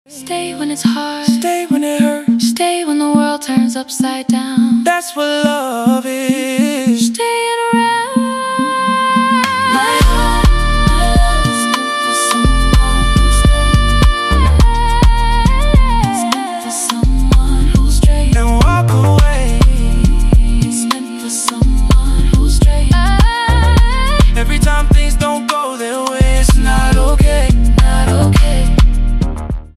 lovesong
soulful